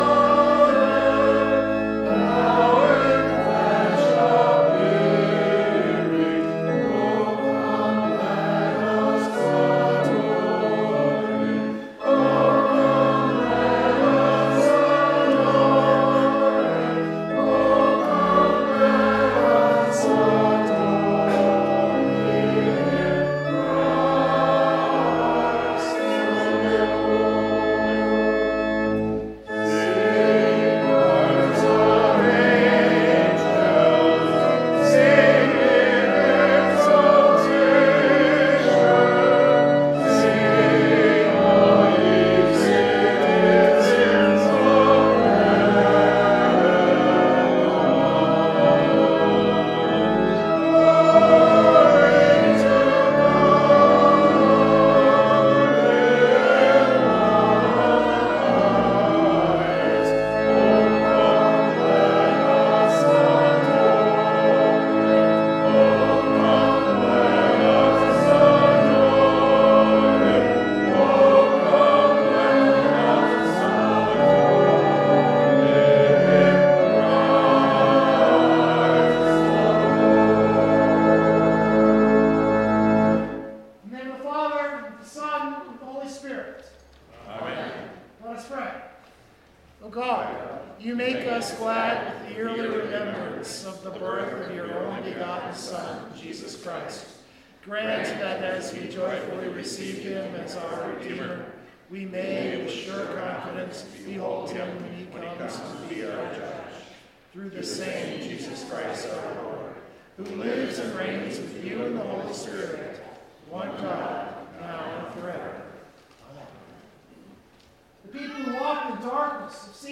The service was lessons and carols – a very traditional Christmas Eve service.